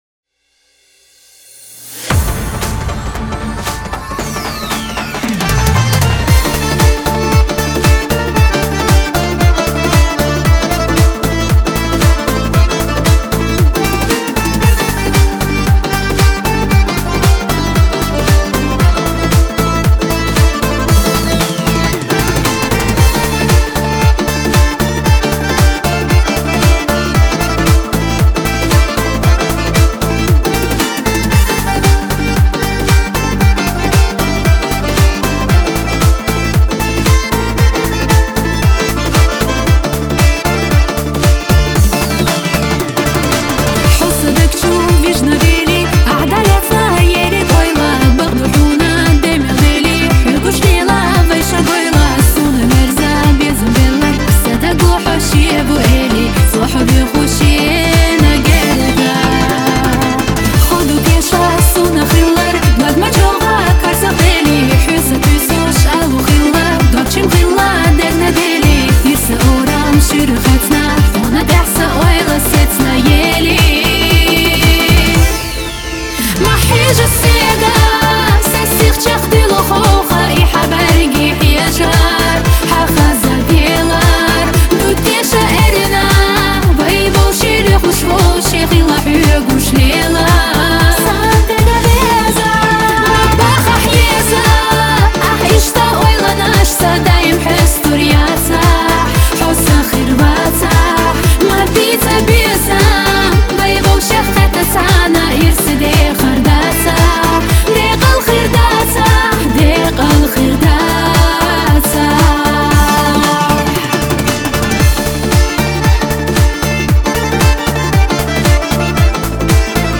Новинки, Кавказская музыка